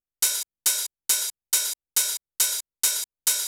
OP HH     -R.wav